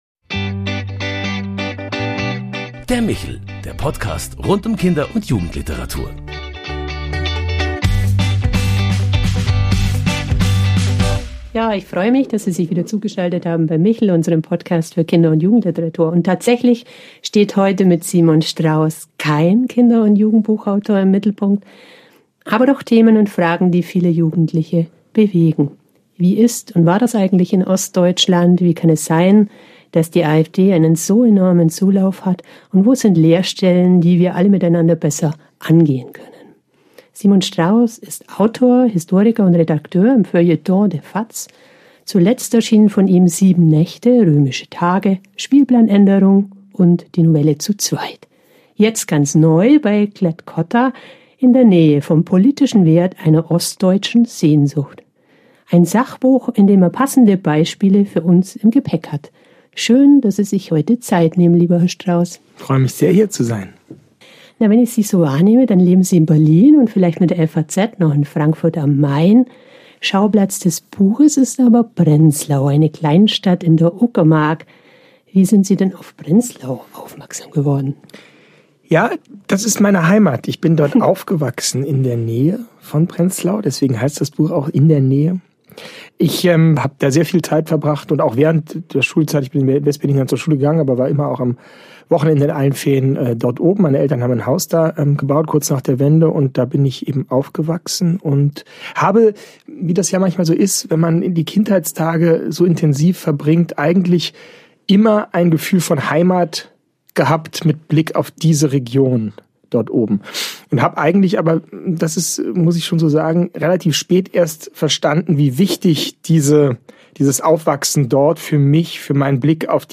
Ein tiefgründiges Gespräch über Heimat, Misstrauen, Hoffnung und das Potenzial kleiner Städte als Trainingsorte für gelebte Demokratie.